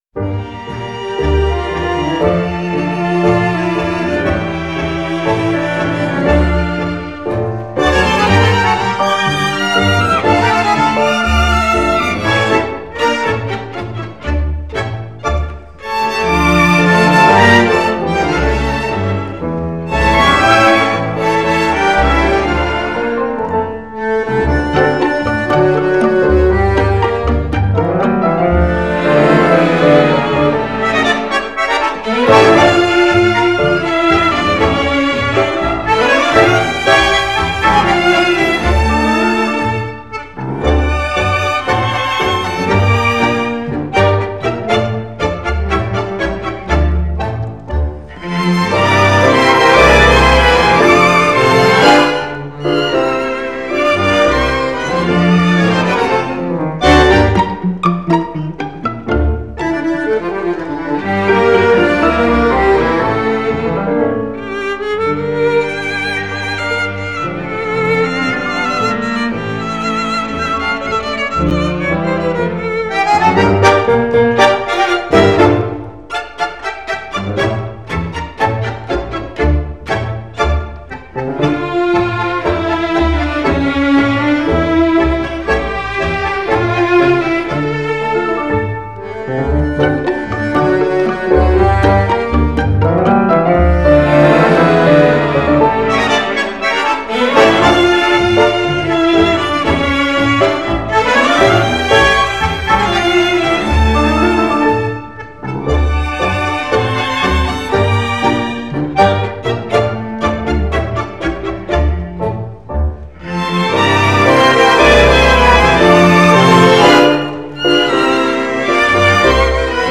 Genre: Tango, Latin